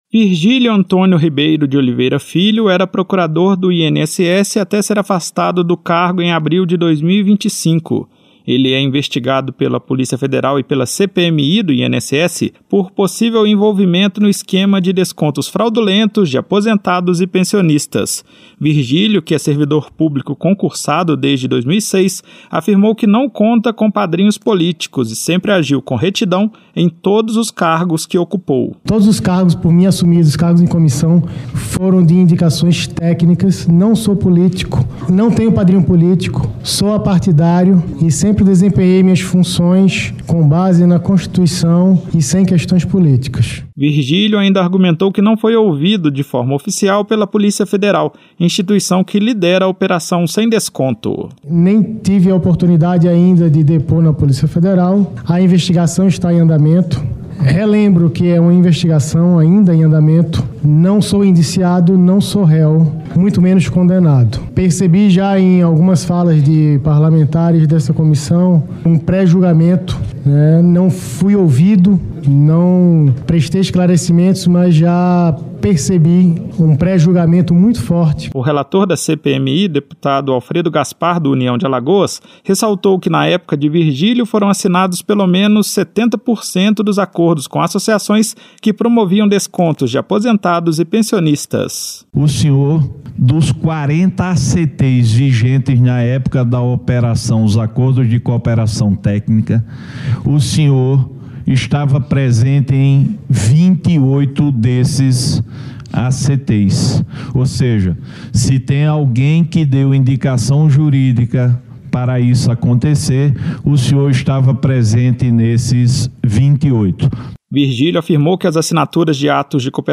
O ex-procurador-geral do INSS, Virgílio Antônio Ribeiro de Oliveira Filho, depôs à CPMI do INSS.